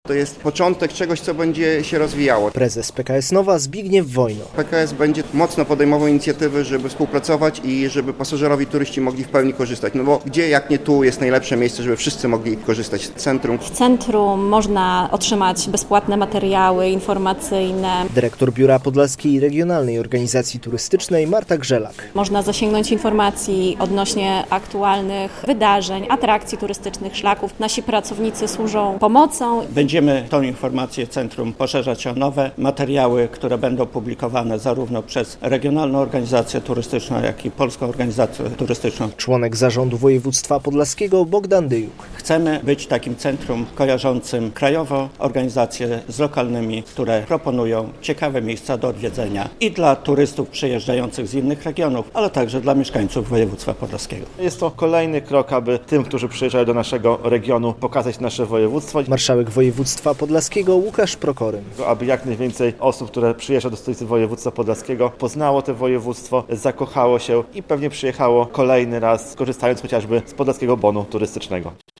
Działa Regionalne Centrum Informacji Turystycznej - relacja